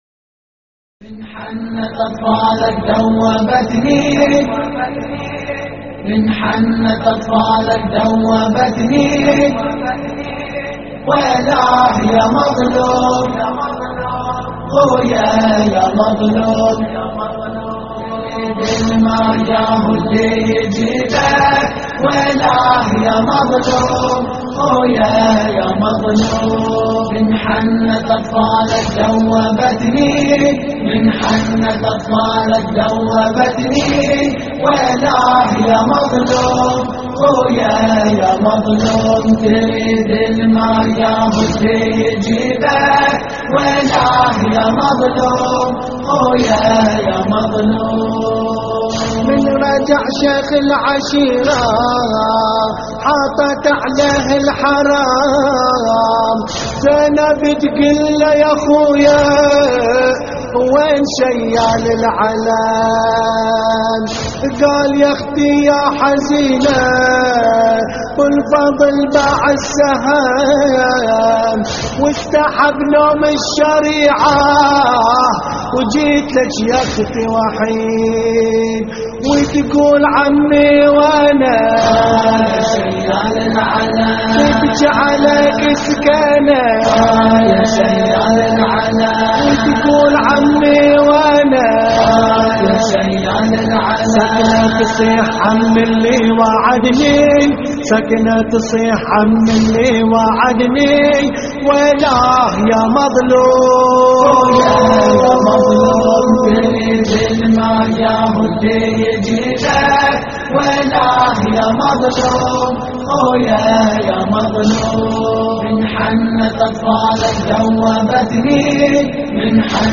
اللطميات الحسينية
اللطميات الحسينية من حنت اطفالك ذوبتني ويلاه يمظلوم - استديو